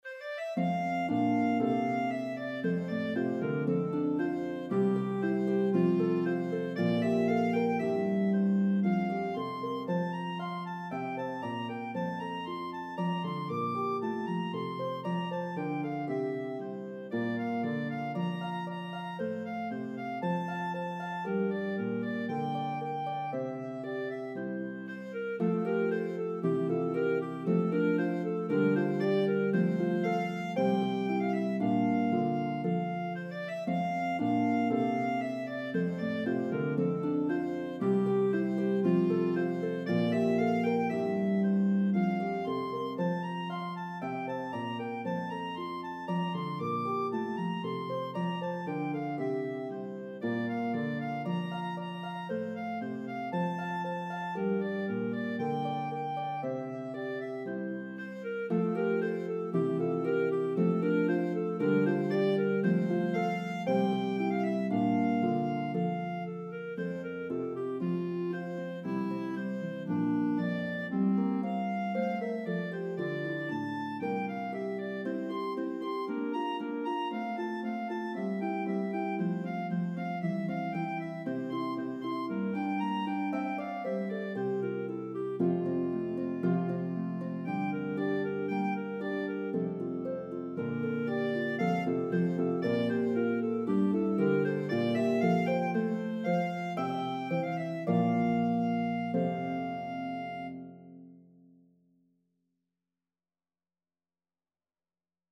Harp and Clarinet version